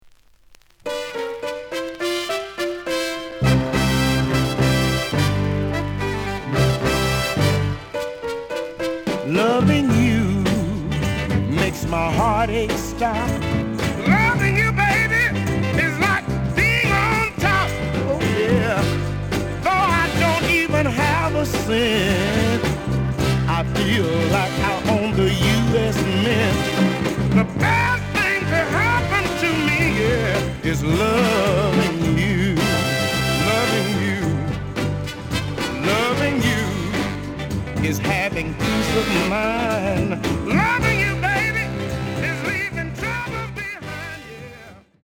The audio sample is recorded from the actual item.
●Genre: Soul, 70's Soul
Looks good, but slight noise on both sides.)